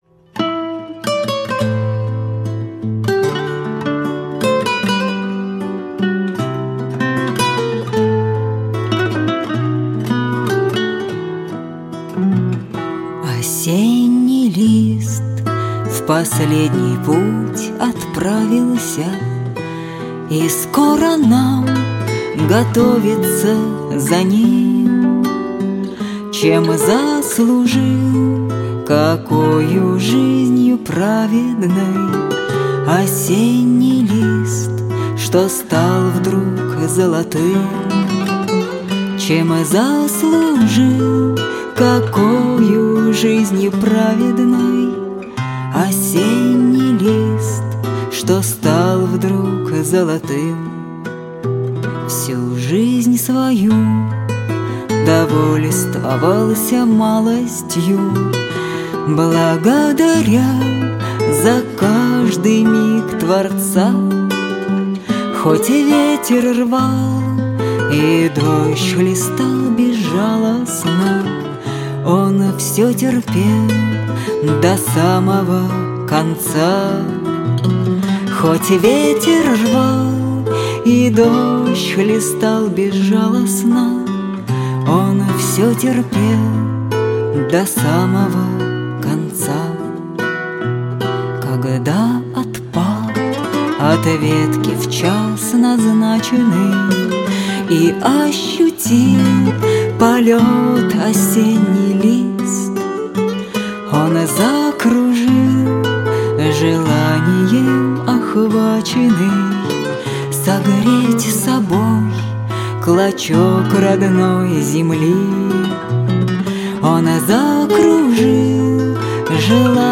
гитара, мандолина.